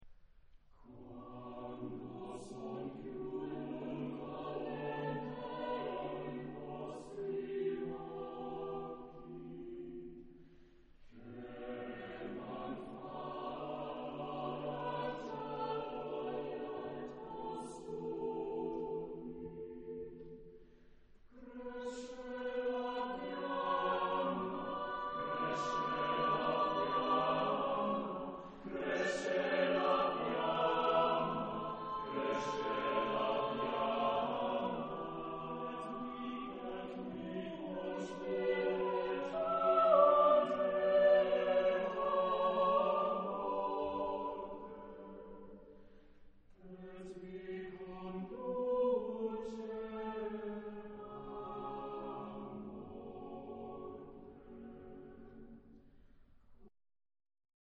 Genre-Style-Forme : Profane ; Chanson d'amour ; Madrigal
Type de choeur : SATB  (4 voix mixtes )